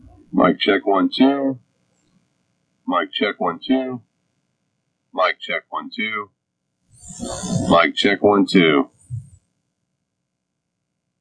Recording sounds hollow or like in space
I wouldn’t call it an echo, but maybe hollow or like I’m in space or a tunnel.
Like talking into a wine glass or milk jug.